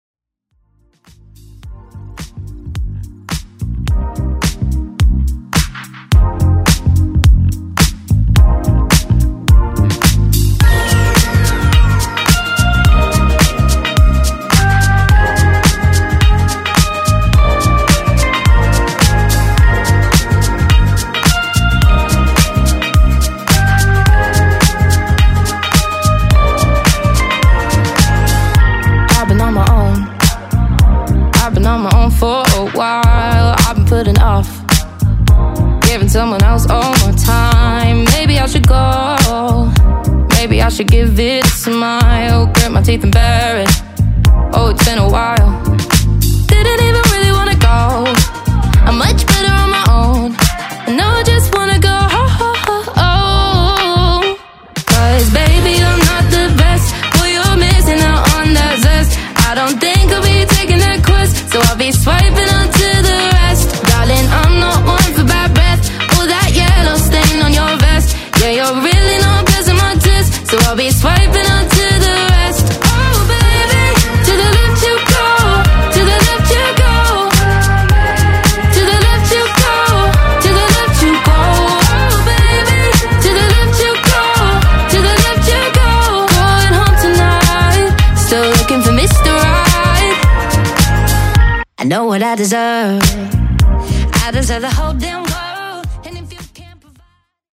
Genres: FUTURE HOUSE , TOP40 , TRANSITIONS AND SEGUES
Clean BPM: 128 Time